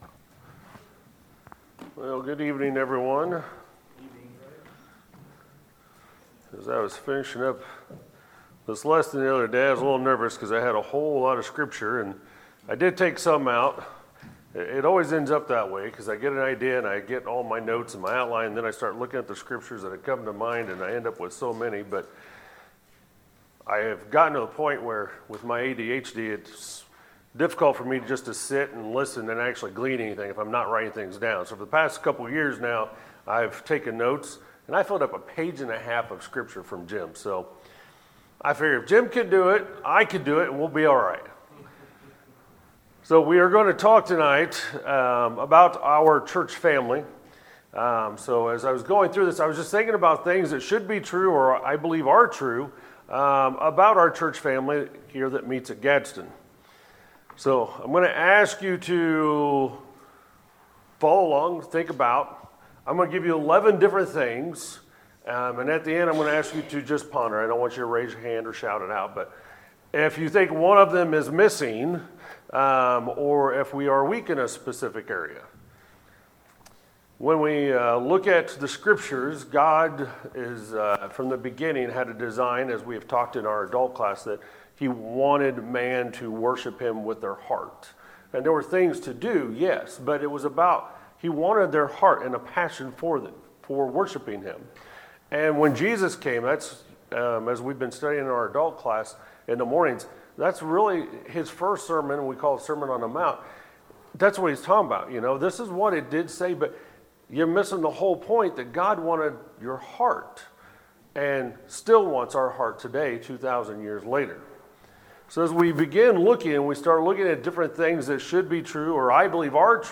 Sermons, February 23, 2020